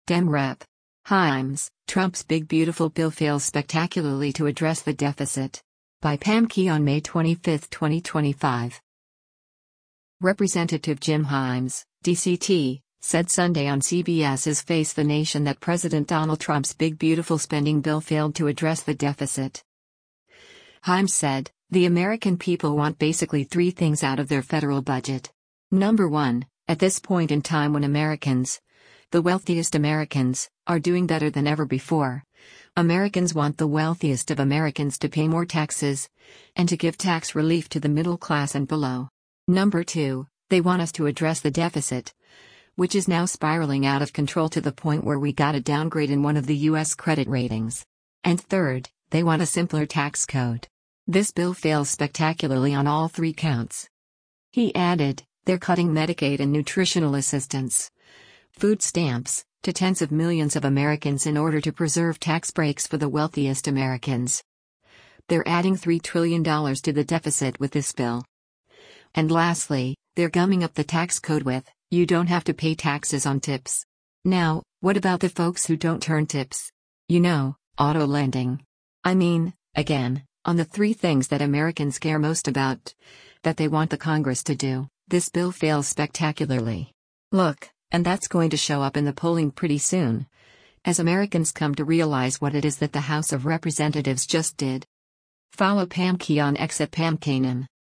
Representative Jim Himes (D-CT) said Sunday on CBS’s “Face the Nation” that President Donald Trump’s big beautiful spending bill failed to address the deficit.